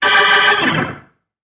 Music Effect; Bluesy B-3 Organ Chord, With Fall.